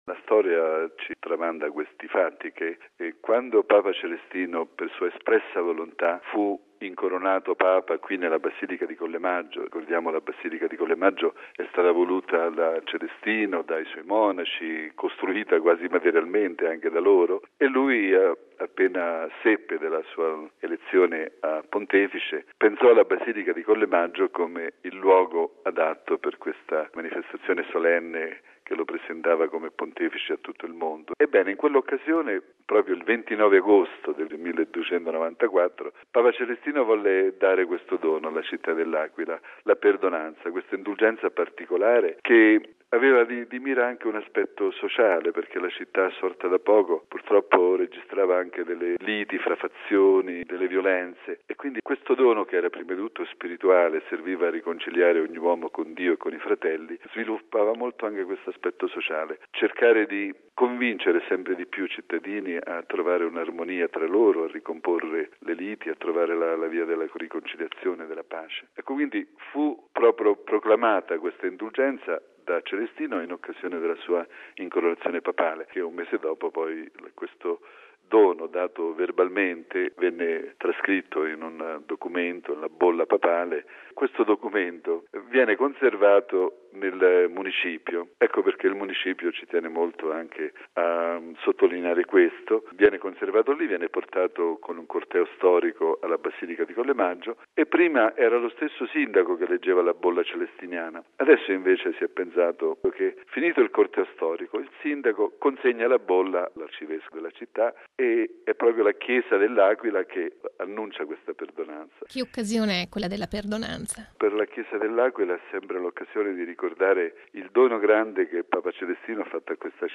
La "perdonanza" di Celestino V, da 700 anni un messaggio di conversione e di concordia sociale per la città dell'Aquila e non solo. Intervista con mons. Giuseppe Molinari